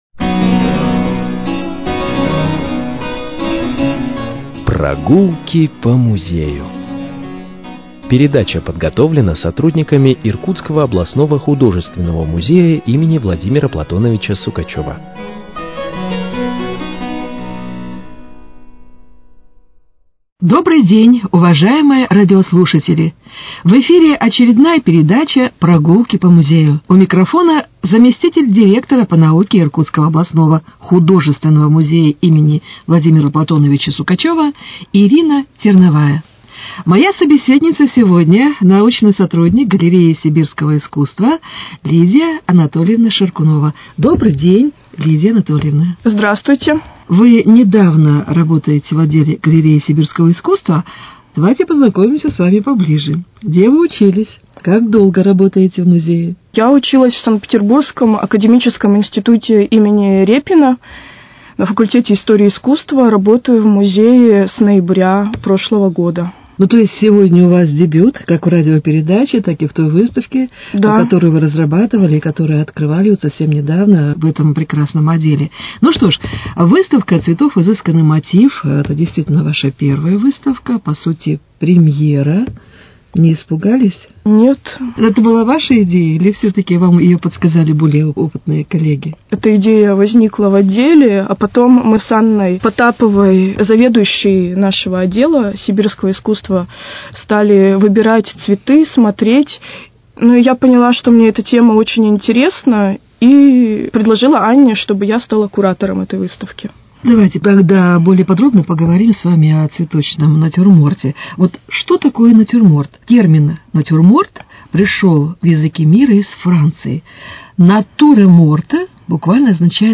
беседует